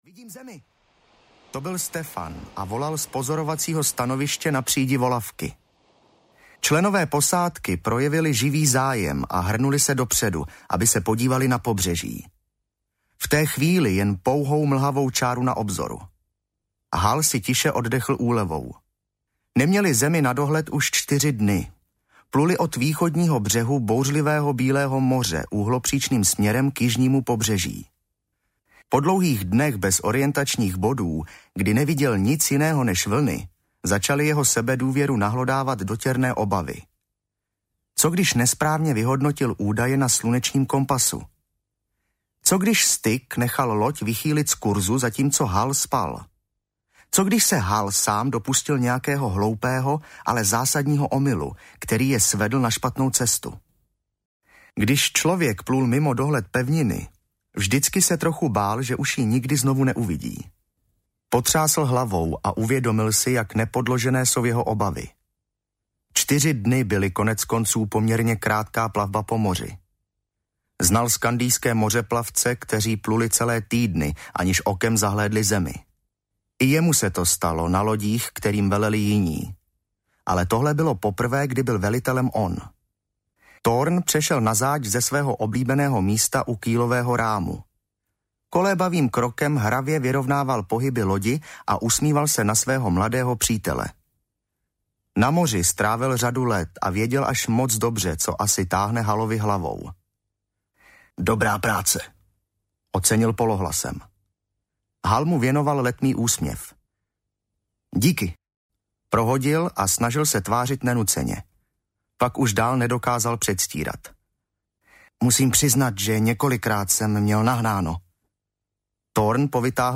Bratrstvo Kniha třetí - Lovci audiokniha
Ukázka z knihy